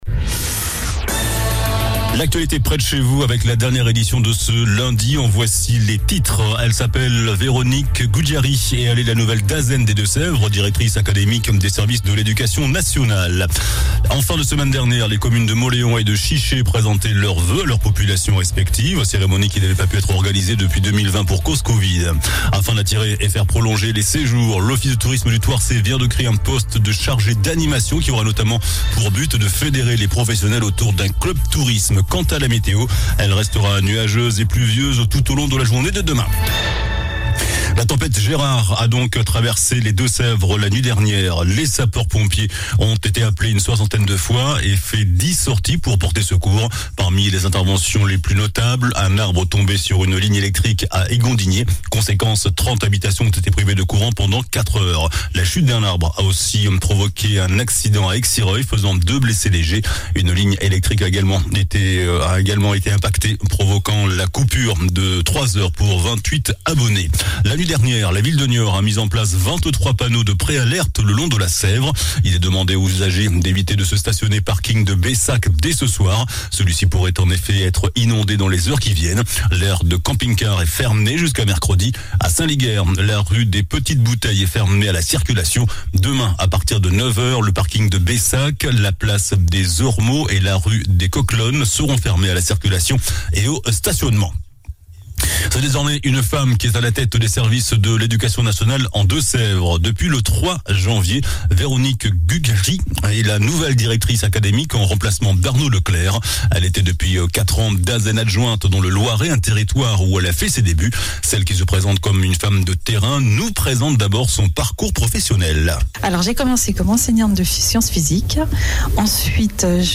JOURNAL DU LUNDI 16 JANVIER ( SOIR )